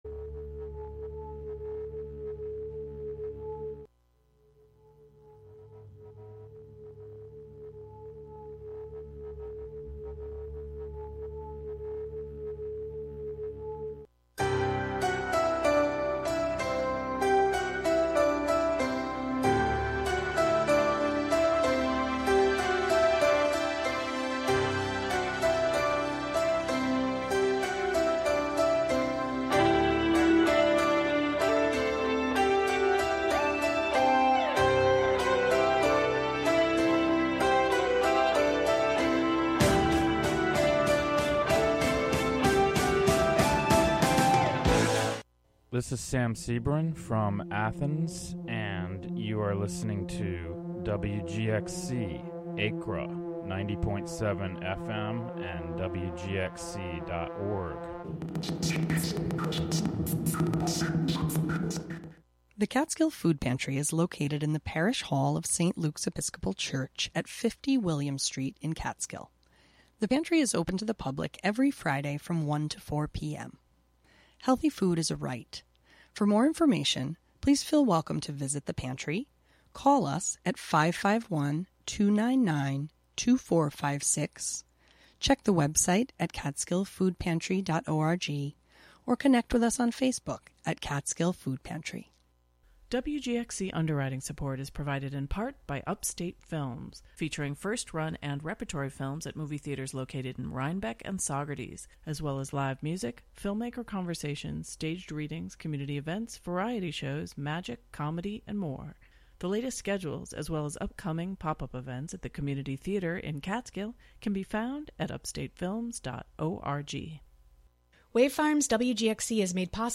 Contributions from many WGXC programmers.
The show is a place for a community conversation about issues, with music, and more.